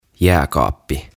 Tuhat sanaa suomeksi - Ääntämisohjeet - Sivu 4
03-Jääkaappi-Fridge.mp3